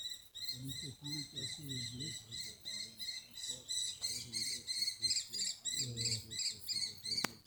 Vogelstimmen: Zaunkönig,
falke.wav